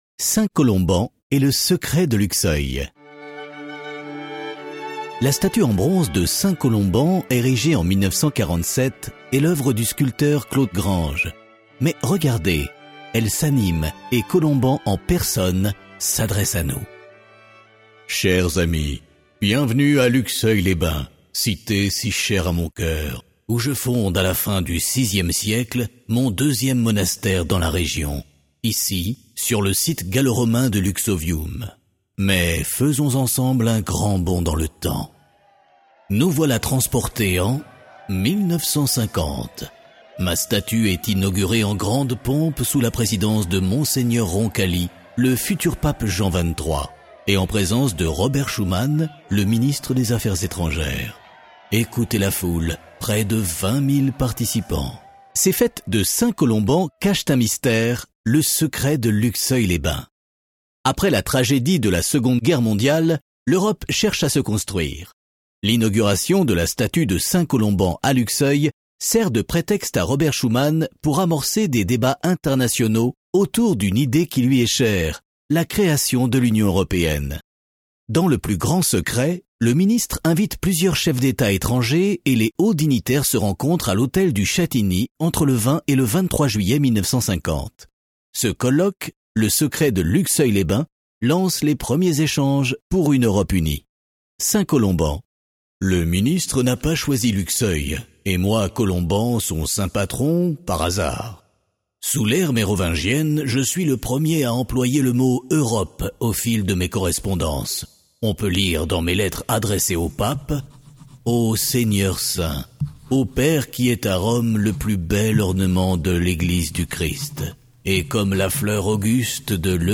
Balade Audio – 13 Saint Colomban & le secret de Luxeuil